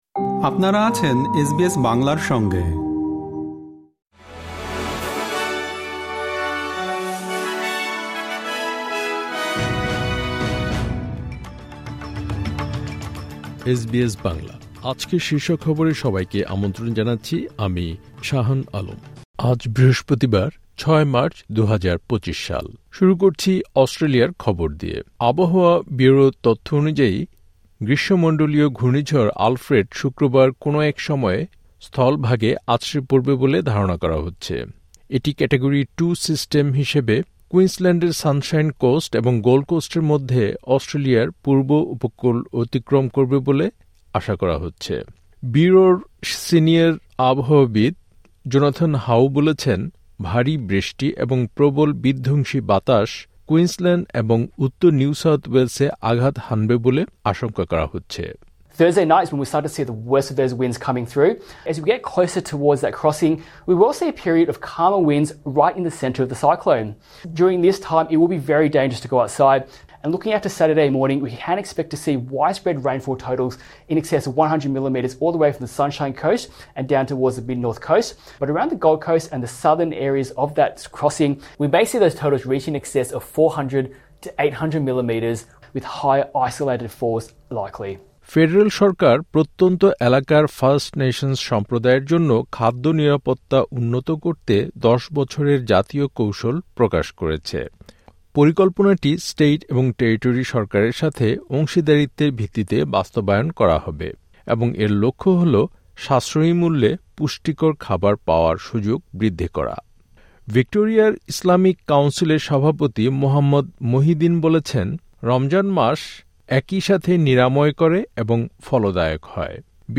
এসবিএস বাংলা শীর্ষ খবর: ৬ মার্চ, ২০২৫